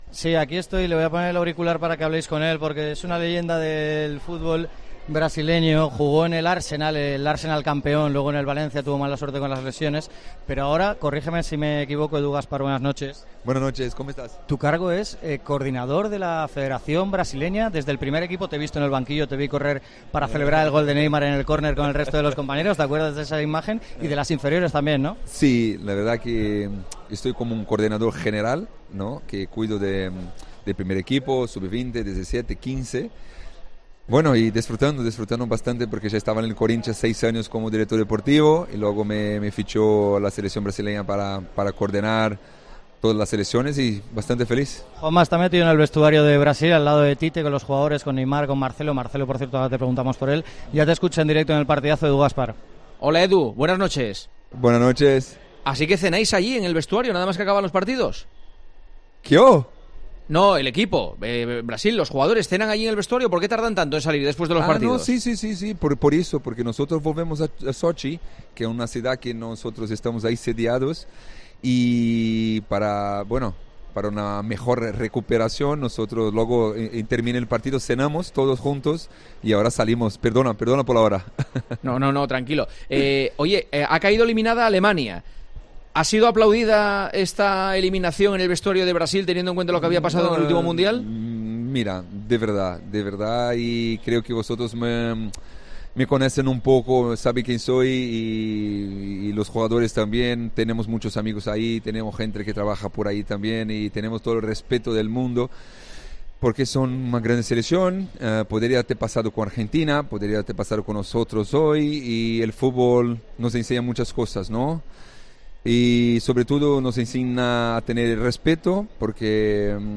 Edu Gaspar, coordinador de la selección de Brasil, habló en El Partidazo de COPE del estado de la 'canarinha': "Tenemos muchos amigos en Alemania, y tenemos mucho respeto porque podría pasarnos también a nosotros, y el fútbol nos enseña a tener respeto. Tenemos que concentrarnos en lo nuestro, y lo de hoy está bien. Marcelo ahora estaba bastante mejor, tenemos que esperar un poquito. Está claro que Neymar está mejorando, como nosotros, incluso estando al 50% ya está mejor que muchos, pero tenemos que cuidarlo".